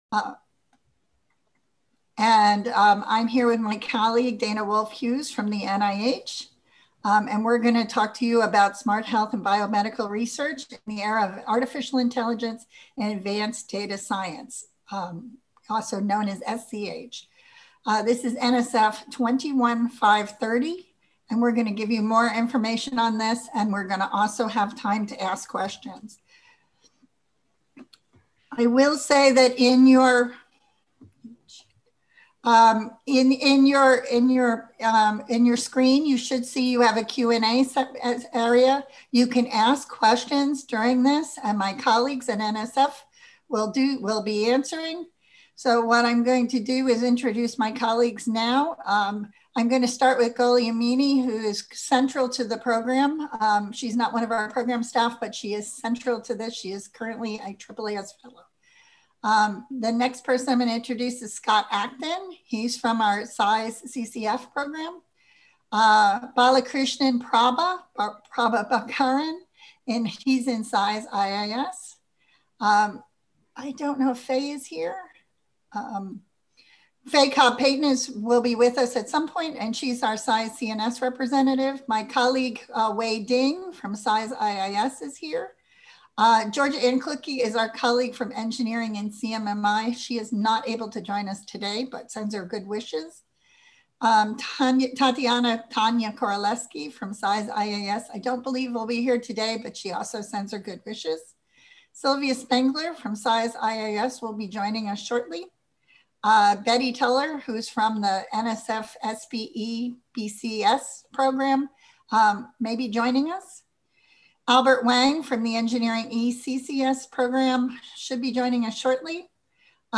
Program Webinar - December 21, 2020 at 3pm